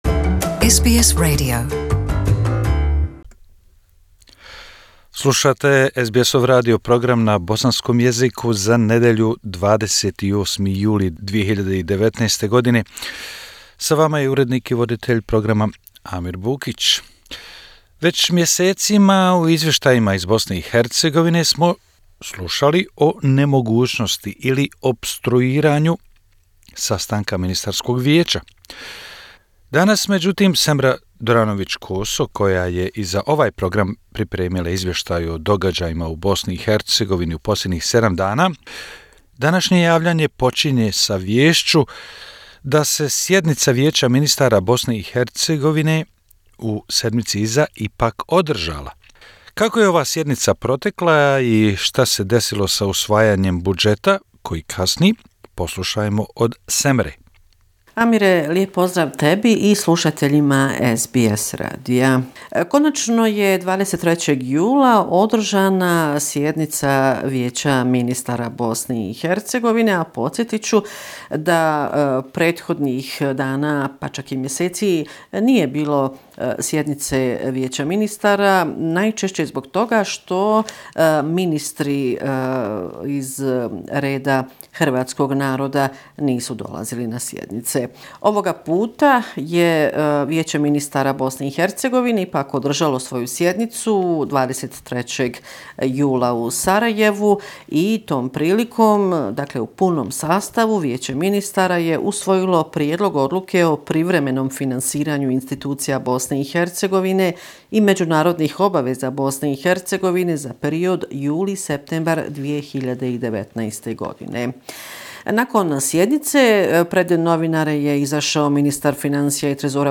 Bosnia and Herzegovina - affairs in the country for the last seven day, weekly report July 28, 2019